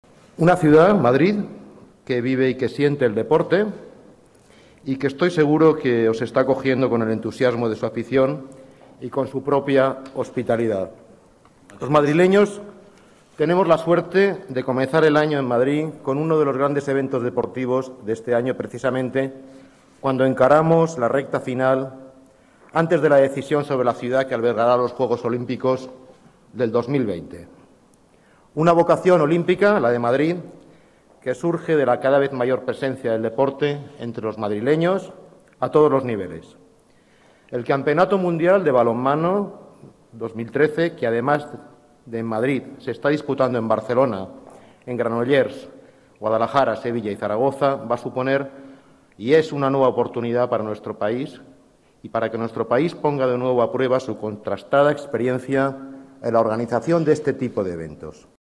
Nueva ventana:Declaraciones del delegado de Las Artes, Deportes y Turismo, Fernando Villalonga